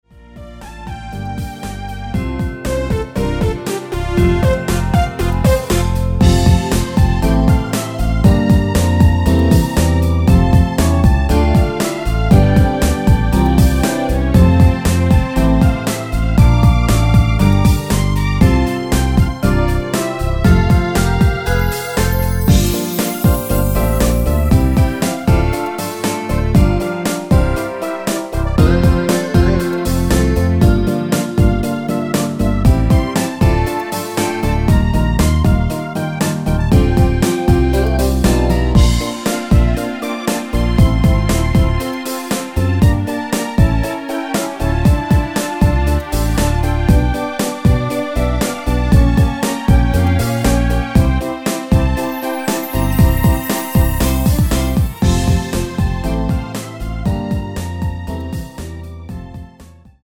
원키 멜로디 포함된 MR 입니다.
음정과 박자 맞추기가 쉬워서 노래방 처럼 노래 부분에 가이드 멜로디가 포함된걸
앞부분30초, 뒷부분30초씩 편집해서 올려 드리고 있습니다.
중간에 음이 끈어지고 다시 나오는 이유는